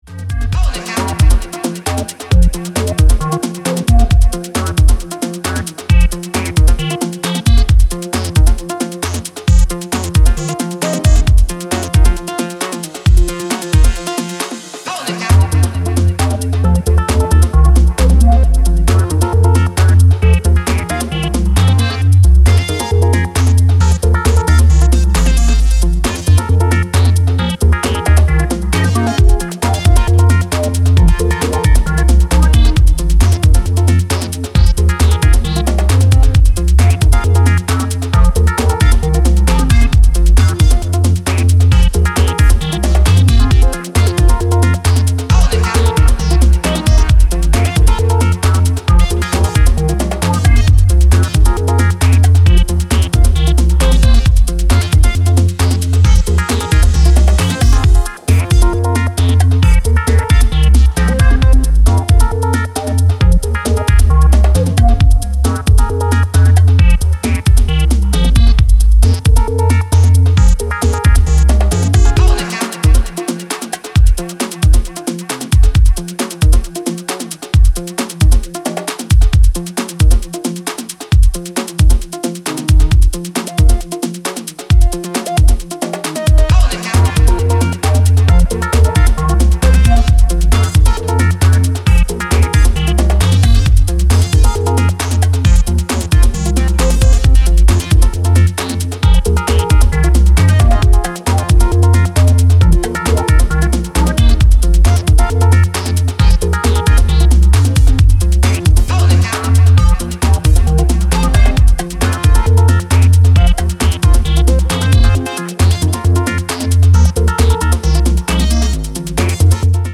ダーティーでユーフォリックなエレクトロ